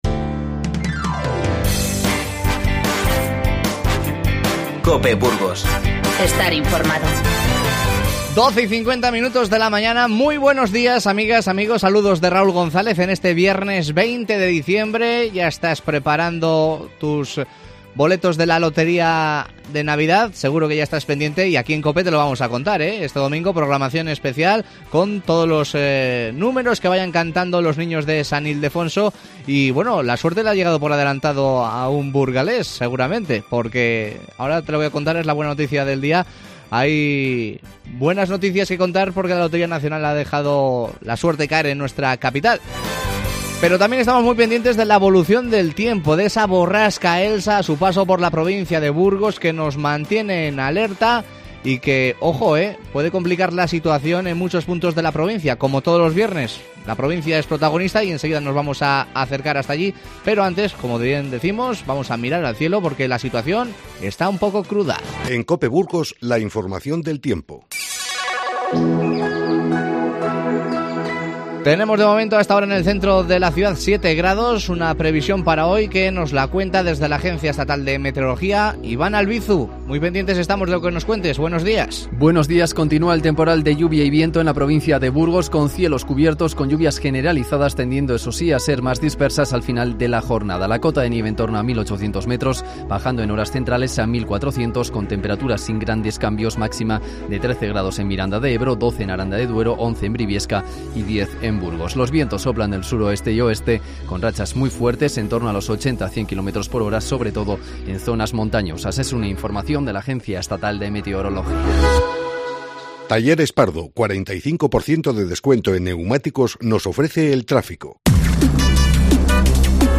Charlamos con su alcalde para conocer la situación en este momento.